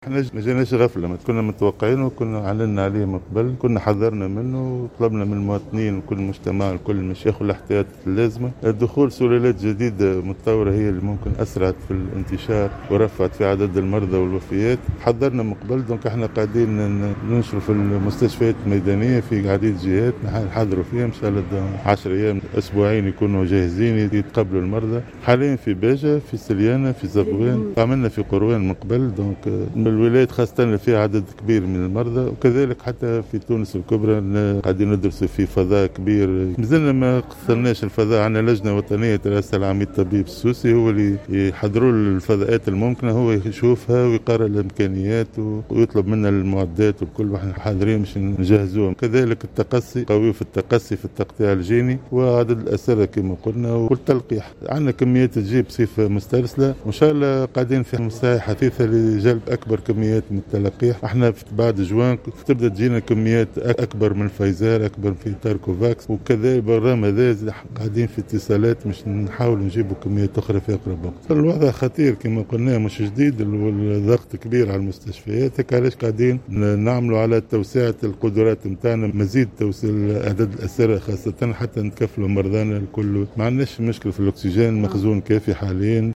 تصريح